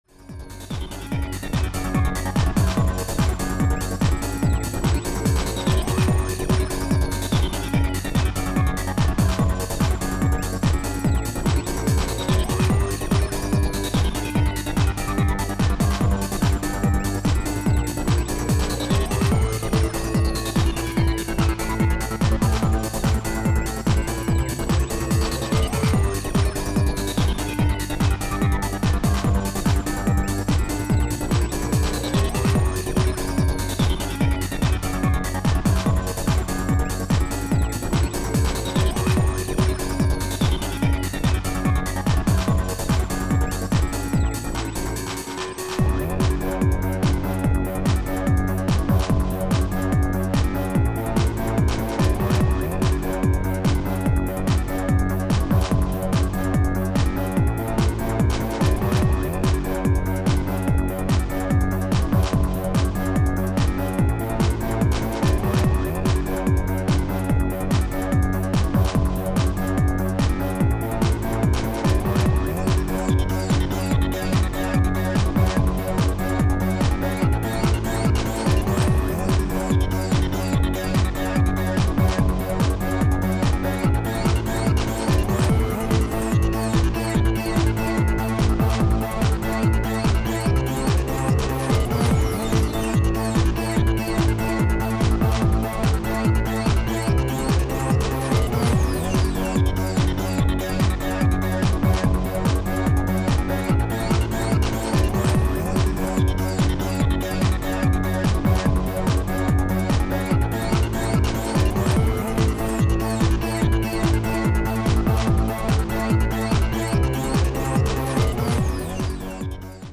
Style: Goa Trance